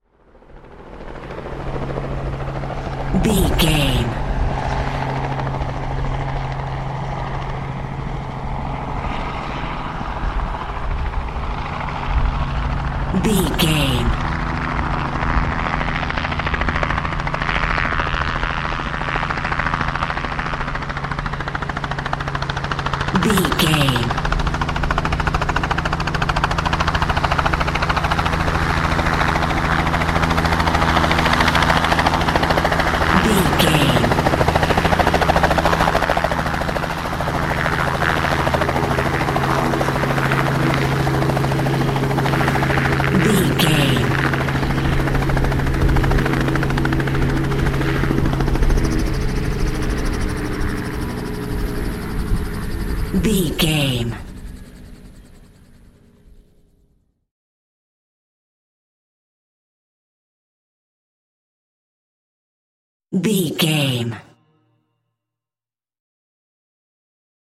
Chopper Landing | VGAME
Stereo hi def audio of small chopper landing onto large field.
Sound Effects
helicopter
rotor
chopper-landing48743.mp3